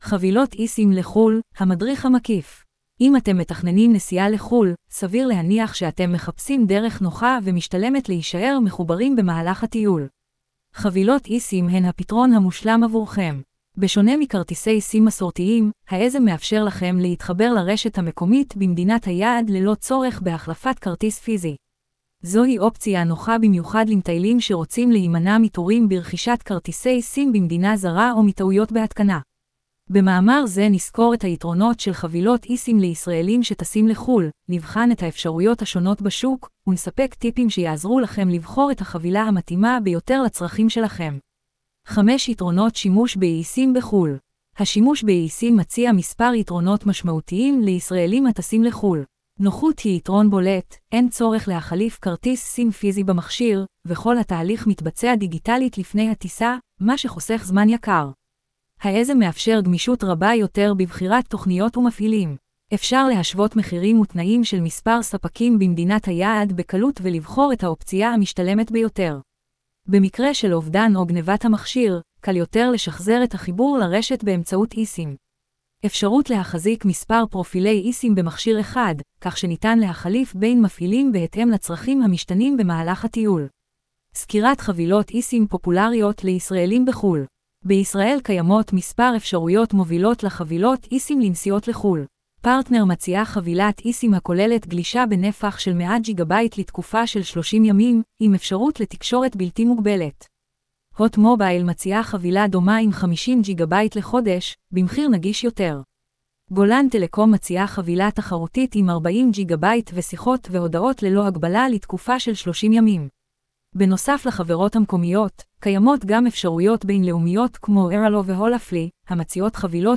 קובץ-אודיו-הקראת-מאמר-חבילות-esim-לחו_ל-מדריך-מקיף.wav